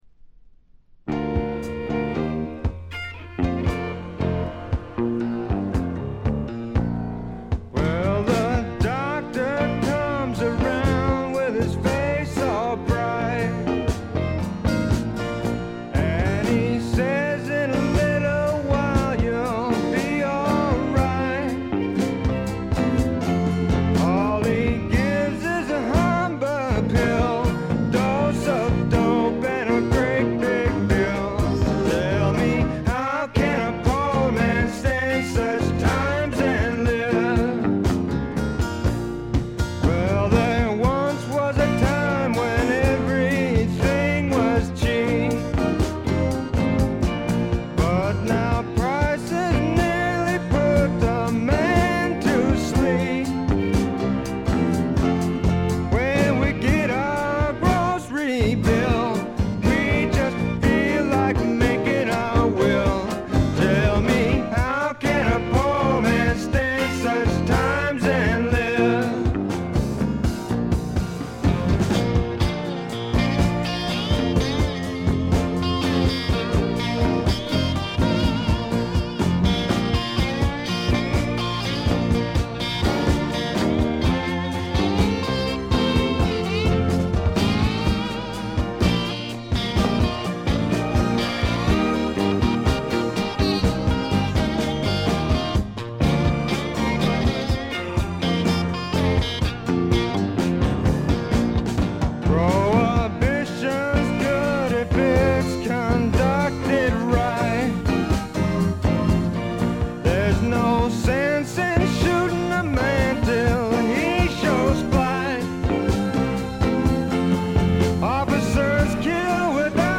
ほとんどノイズ感無し。
試聴曲は現品からの取り込み音源です。
Guitar, Mandolin, Bass